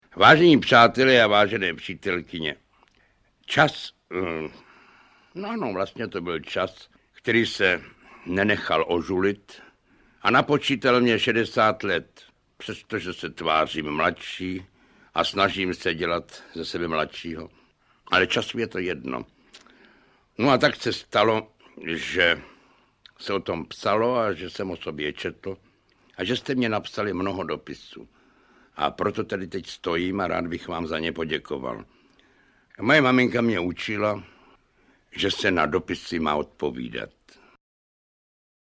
Ukázka z knihy
• InterpretJan Werich, Jiří Voskovec
tak-se-ti-hlasim-audiokniha